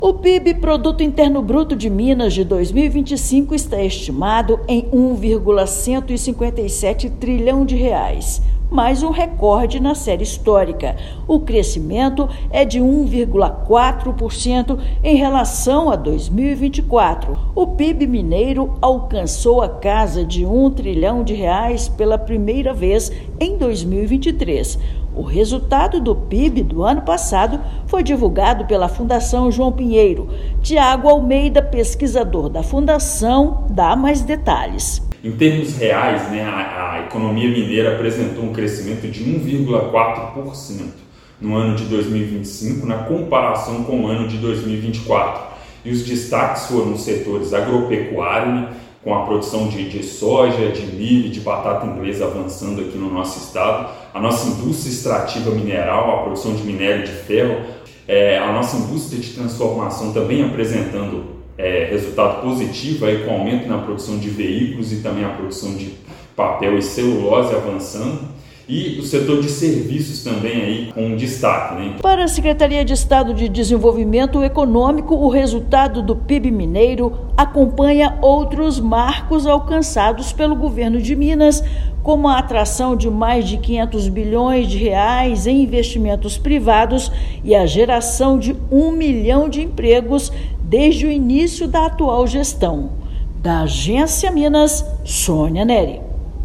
Crescimento real de 1,4% foi puxado pelo desempenho da agropecuária, da indústria extrativa mineral e dos serviços. Ouça matéria de rádio.